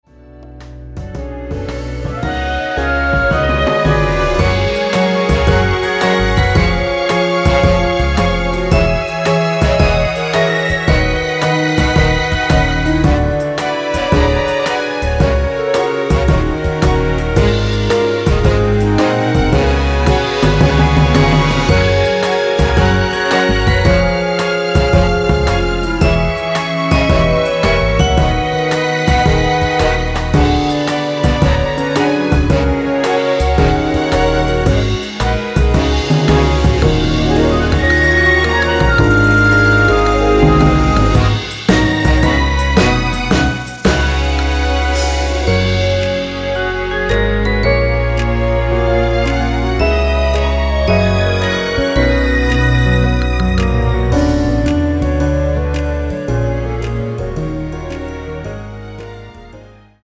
다음 간주가 길어서 4마디로 짧게 편곡 하였습니다.(미리듣기및 본문가사 확인)
원키에서(-8)내린 (1절+후렴)으로 진행되는 멜로디 포함된 MR입니다.
Gb
앞부분30초, 뒷부분30초씩 편집해서 올려 드리고 있습니다.
중간에 음이 끈어지고 다시 나오는 이유는